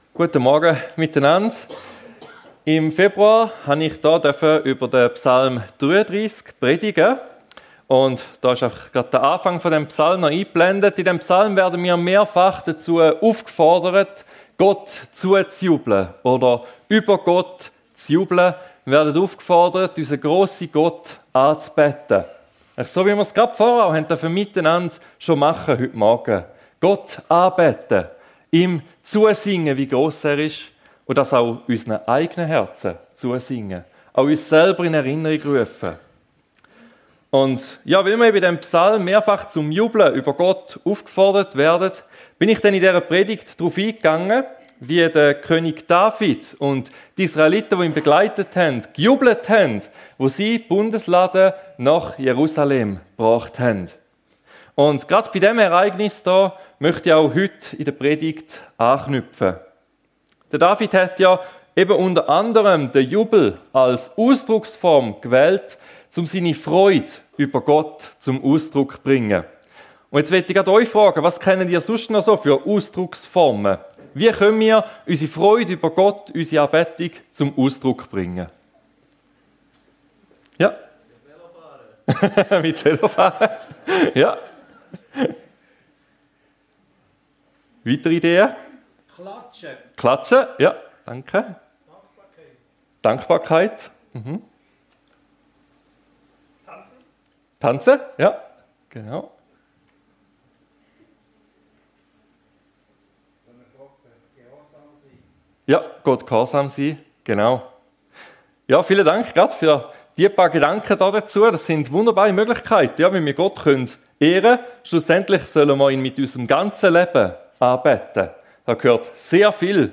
Vielfalt der Anbetung ~ FEG Sumiswald - Predigten Podcast